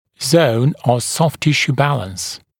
[zəun əv sɔft ‘tɪʃuː ‘bæləns][зоун ов софт ‘тишу: ‘бэлэнс]зона баланса мягких тканей